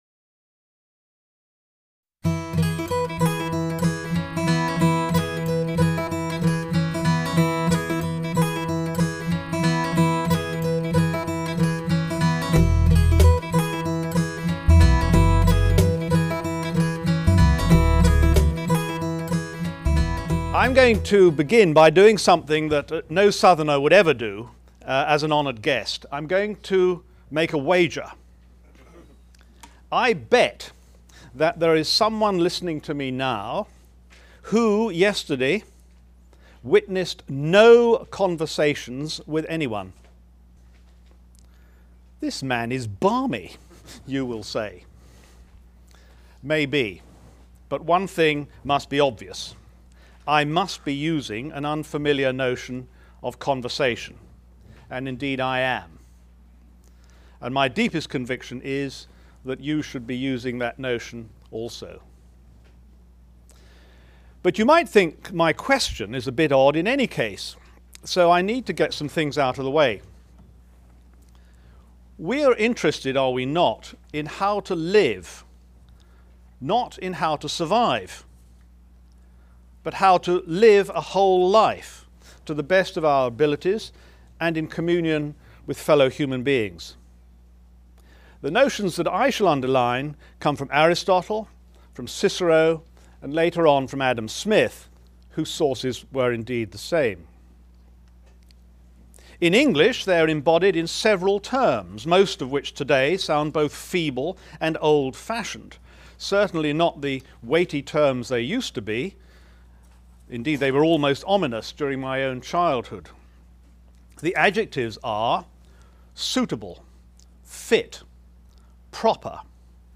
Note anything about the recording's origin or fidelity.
This lecture was recorded at the Abbeville Institute’s 2006 Summer School: The Southern Agrarian Tradition.